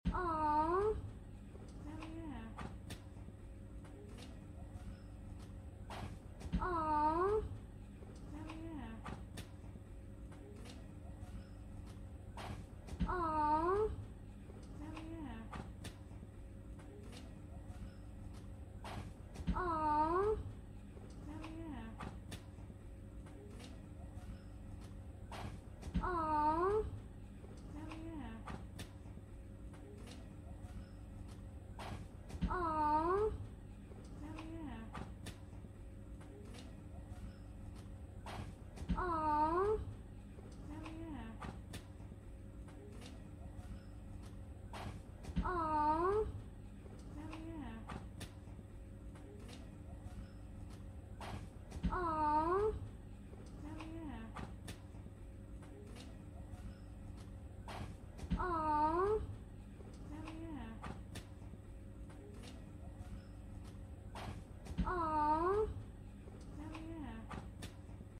When Girls See A Mouse Sound Effects Free Download